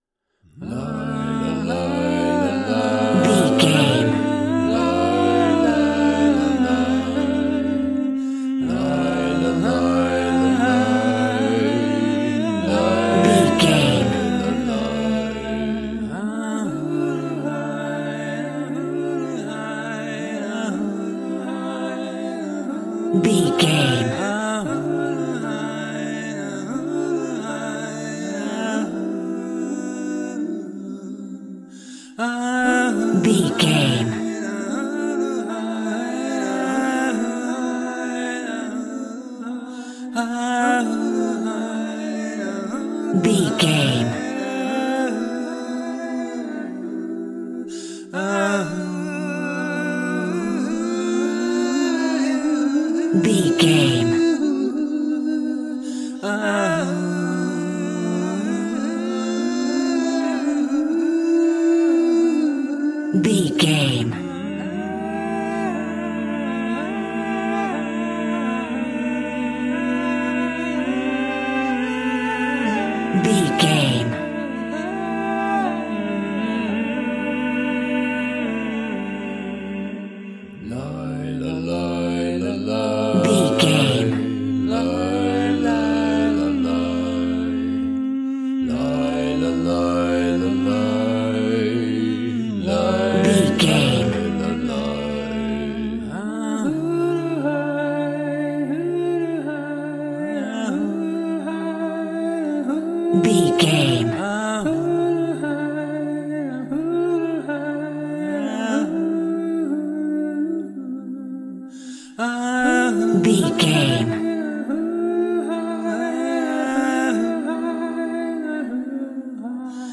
Aeolian/Minor
Slow
tranquil
synthesiser
drum machine